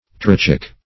Trochaic \Tro*cha"ic\, n. (Pros.)